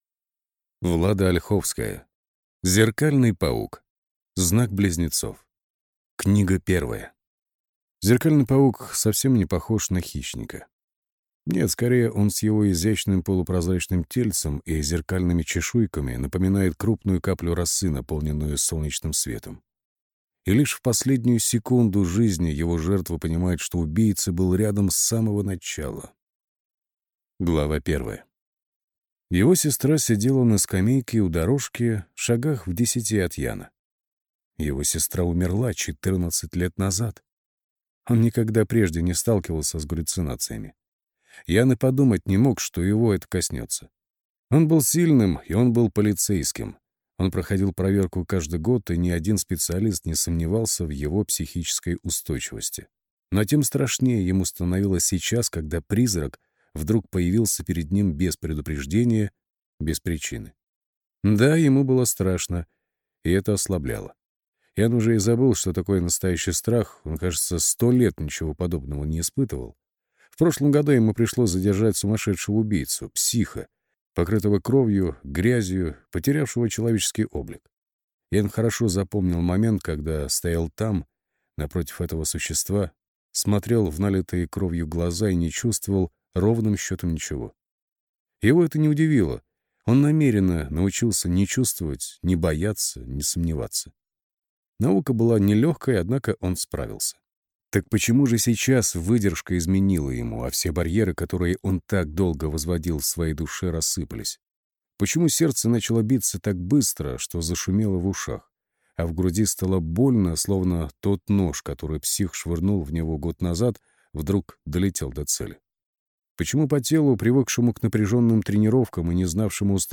Аудиокнига Зеркальный паук | Библиотека аудиокниг